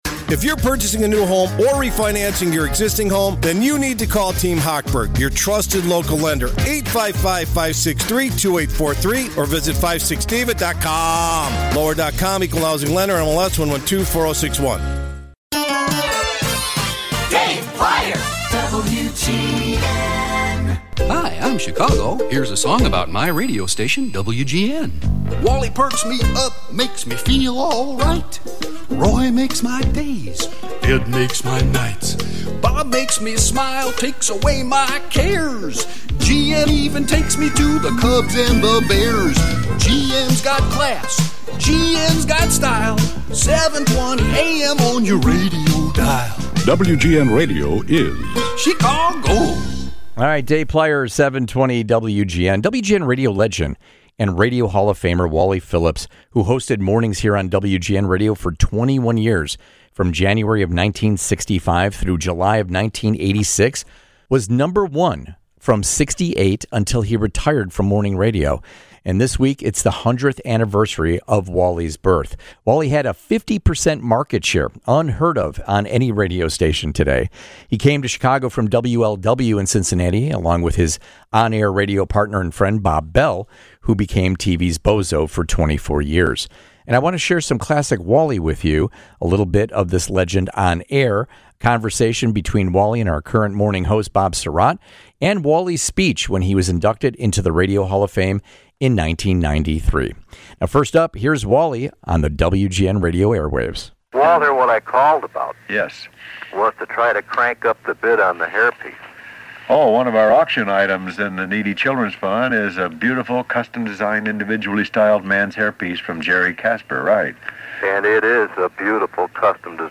interview
acceptance speech in 1993 for the Radio Hall of Fame.